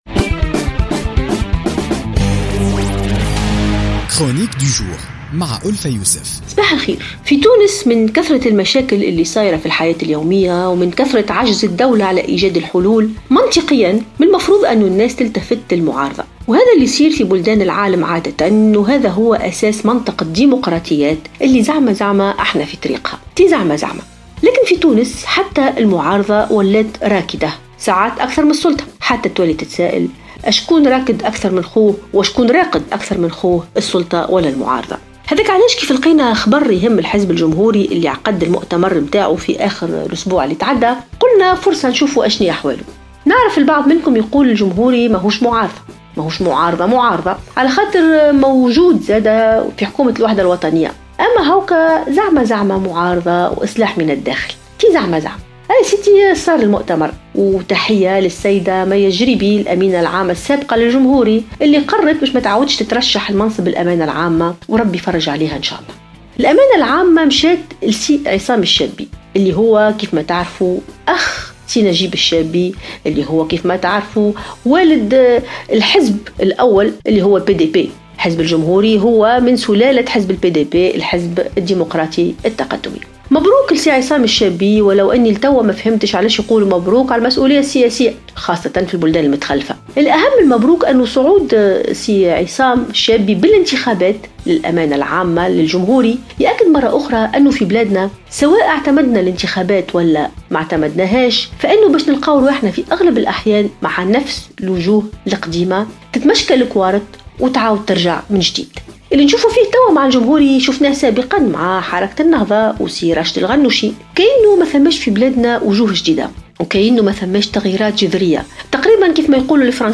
تطرقت الكاتبة ألفة يوسف في افتتاحية اليوم الأربعاء 8 فيفري 2017 إلى دور المعارضة في تونس الذي يبدو باهتا أكثر من دور الحكومة الباهت في الأصل .